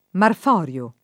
Marforio [ marf 0 r L o ]